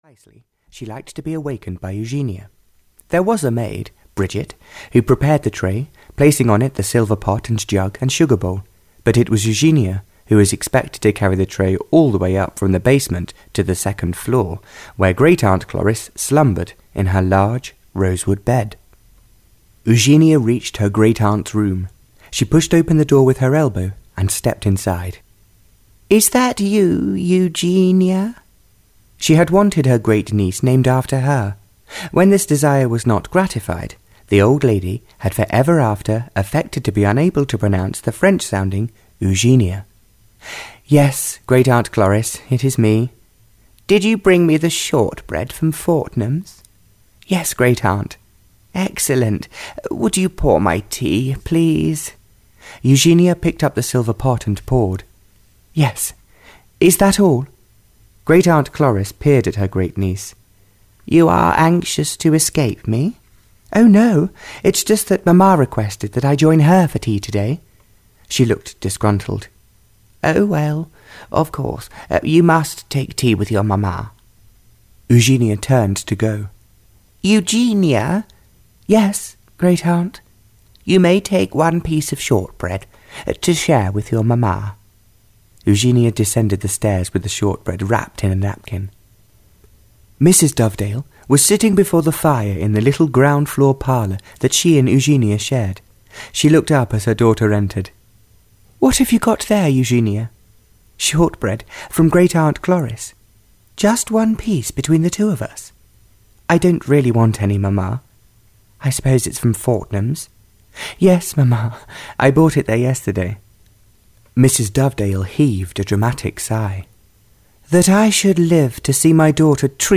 Audio knihaThe House of Happiness (Barbara Cartland's Pink Collection 21) (EN)
Ukázka z knihy